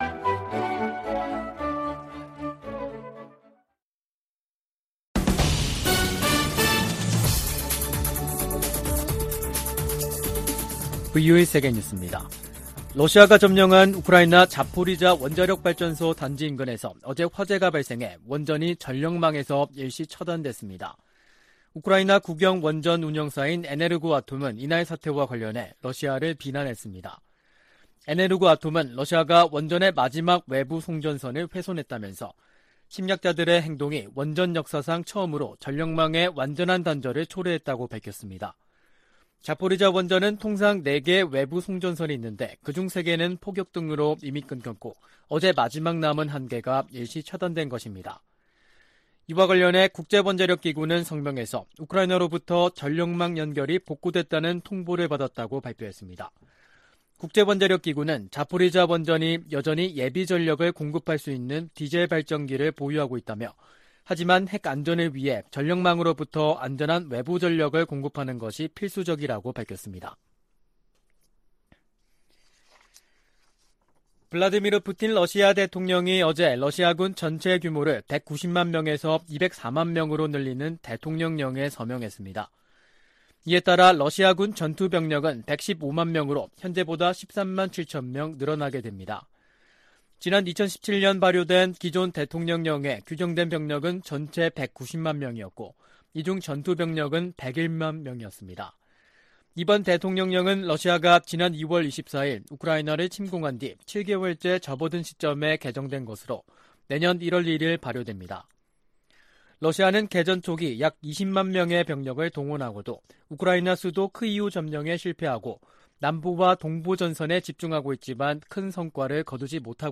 VOA 한국어 간판 뉴스 프로그램 '뉴스 투데이', 2022년 8월 26일 3부 방송입니다. 미 국무부 차관보와 한국 외교부 차관보가 서울에서 회담하고 북한의 도발 중단과 대화 복귀를 위한 공조를 강화하기로 했습니다. 미 국무부는 반복되는 러시아와 중국 폭격기의 한국 방공식별구역 진입을 역내 안보에 대한 도전으로 규정했습니다. 미국과 한국 정부가 중국 내 탈북 난민 상황을 거듭 우려하며 중국 정부에 난민 보호에 관한 국제의무 이행을 촉구했습니다.